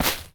Footstep2.wav